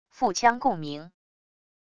腹腔共鸣wav音频